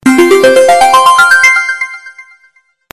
جلوه های صوتی
SMS Tones